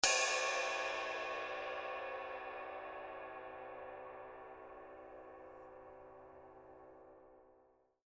Caractère du son: Brillant, dur, énergique. Spectre assez étroit, mix légèrement complexe. Sensations lourdes. Ping vitreux, puissant qui devient plus sec, plus brut et boisé dans la zone non tournée et plus expressif, croustillant et riche dans la zone tournée. Halo assez brut et terreux qui est très serré et contrôlé. Cloche lourde et perçante. Cymbale ride tranchante et variée avec des zones de jeux distinctes pour une utilisation articulée dans les sets lourds.
signature_20_duo_ride_outer_body.mp3